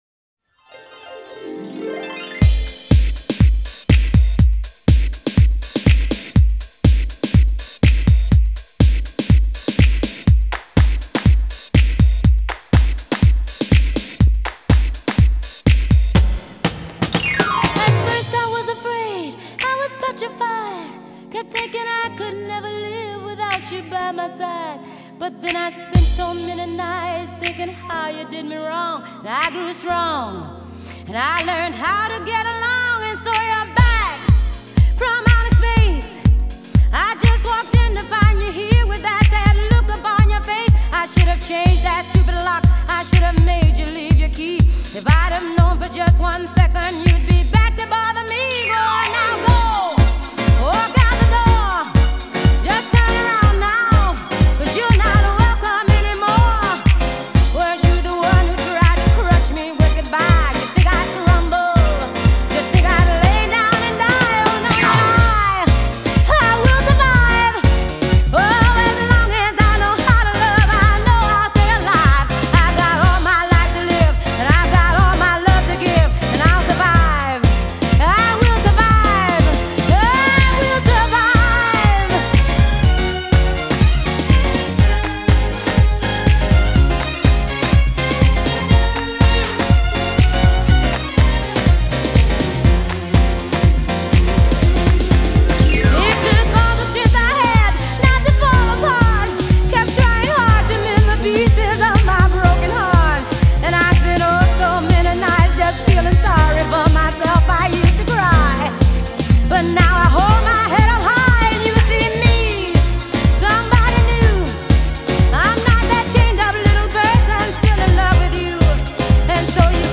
you can hear it in those piano riffs